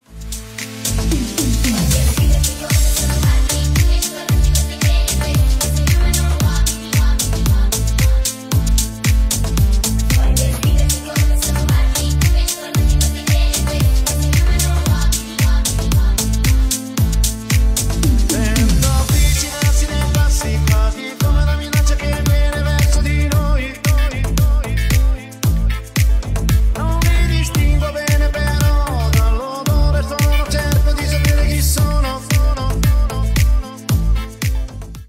• Качество: 320 kbps, Stereo
Ремикс
Поп Музыка